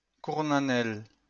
Fichier audio de prononciation du projet Lingua Libre